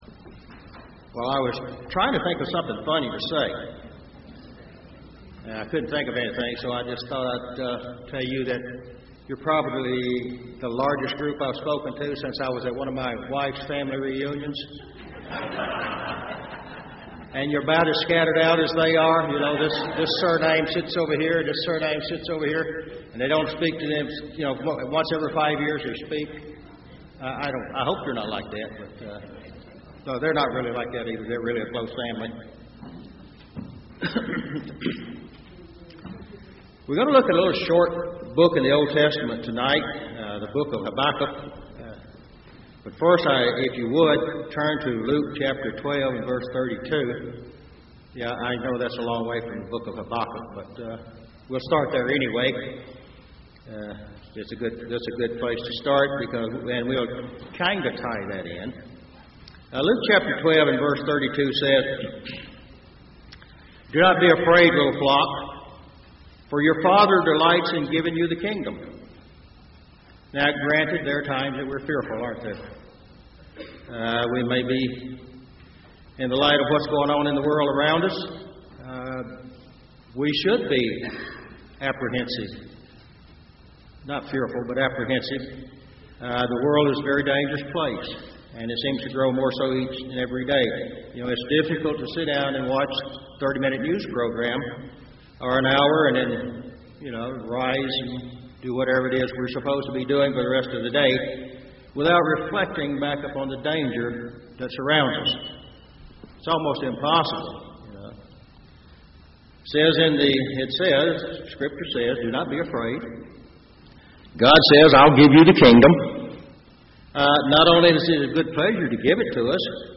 Bible Study
This sermon was given at the Gatlinburg, Tennessee 2013 Feast site.